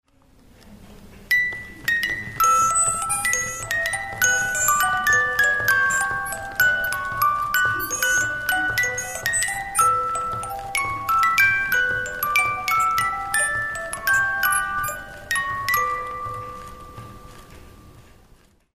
Ne pas confondre non plus la vielle avec les boites à musique, pourtant aussi muni d'une manivelle mais offrant une musique bien différente: Ecoutez un extrait de